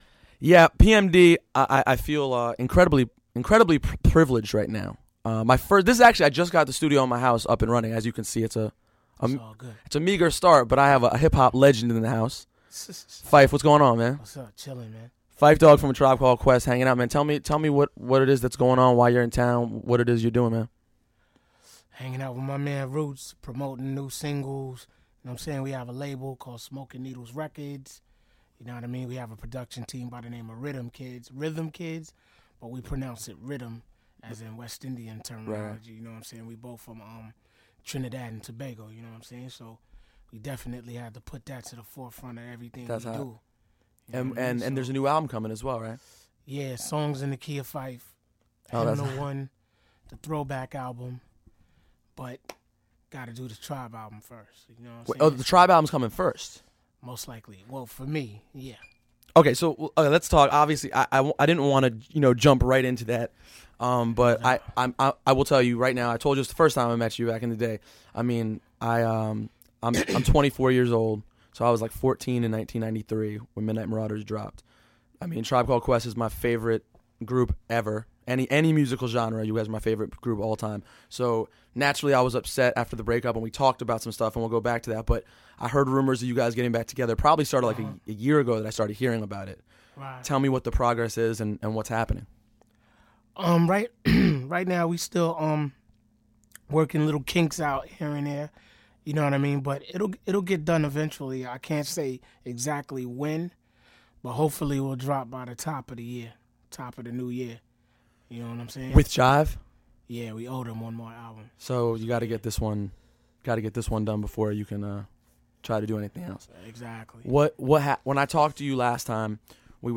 Back in 2004, Phife Diggy came by my crib in Maryland and we talked about everything. I just found the interview and thought I would share.